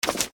gen_click.mp3